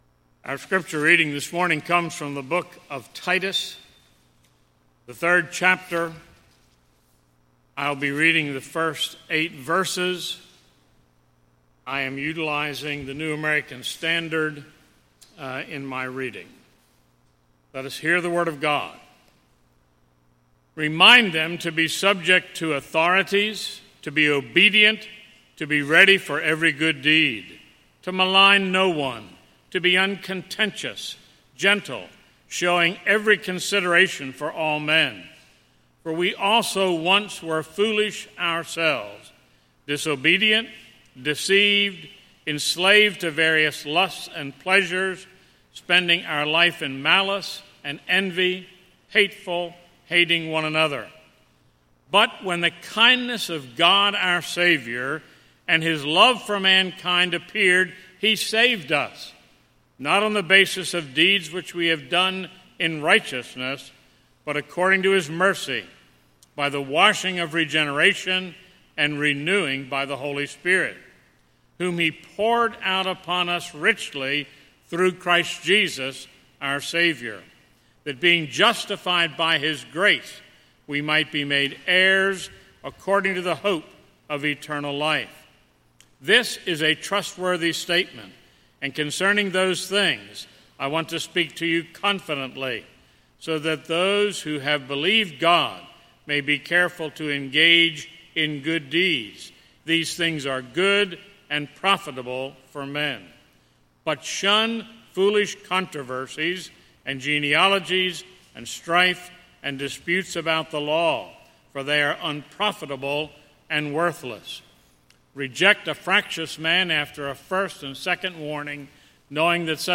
Sermon on Titus 3:1-8 from October 26